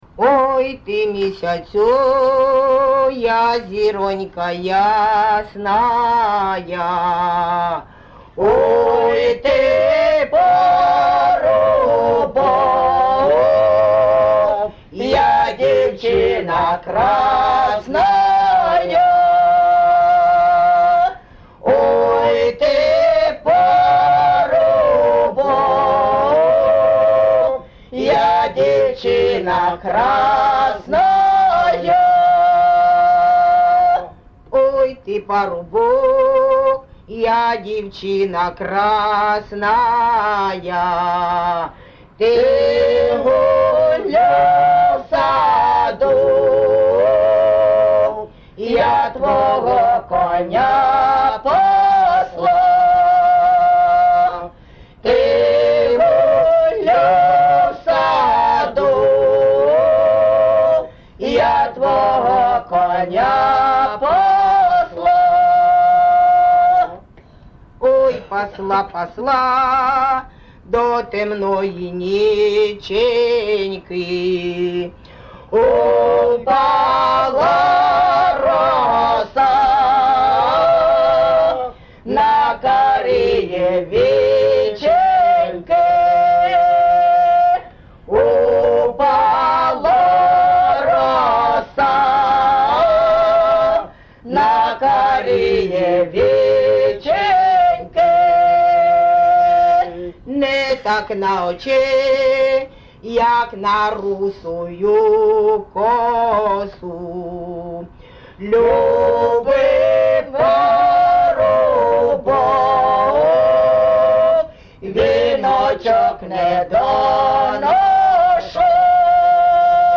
ЖанрПісні з особистого та родинного життя
Місце записус-ще Красноріченське, Кремінський район, Луганська обл., Україна, Слобожанщина